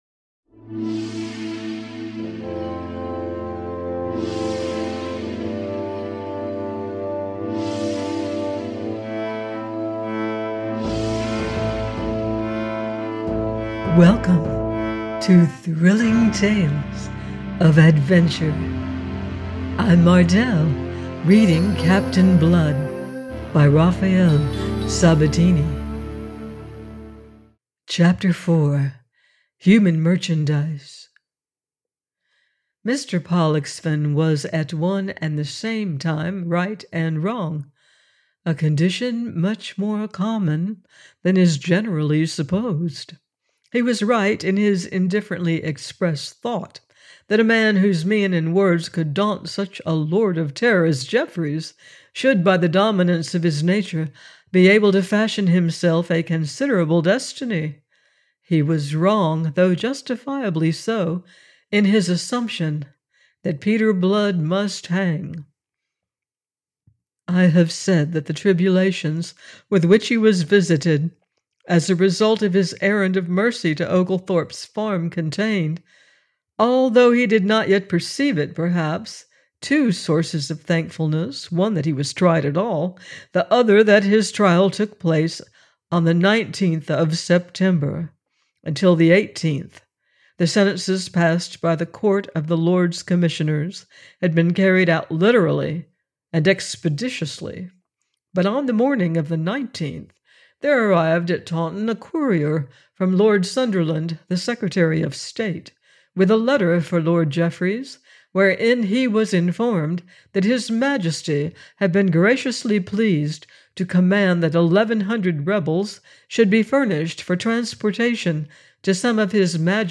Captain Blood – by Raphael Sabatini - audiobook